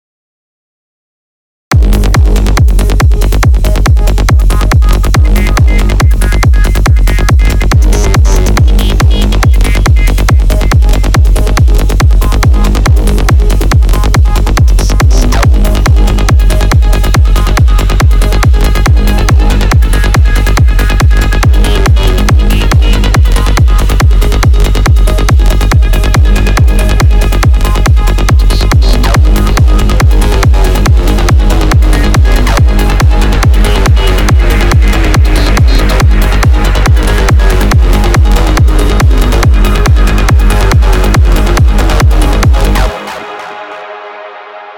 I haven't posted music in a bit so... A while ago, when I was figuring out Surge XT accessibility, I thought I should make some Psytrance.